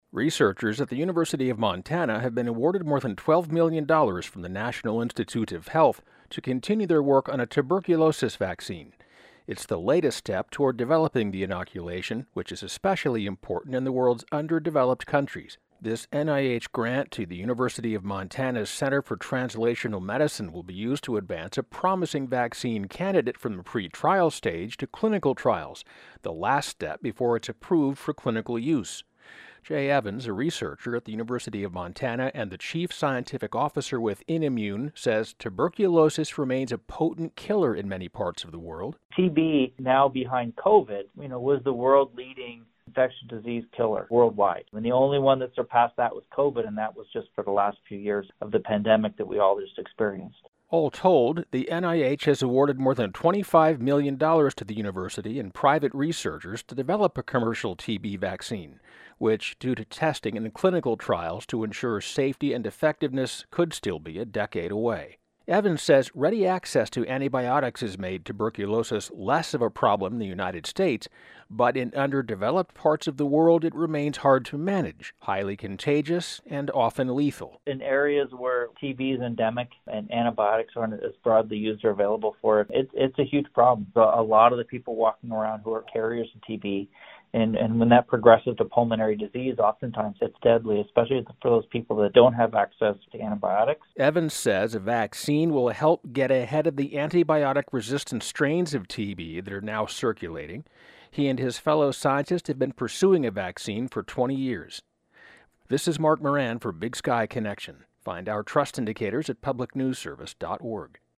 Category: City Desk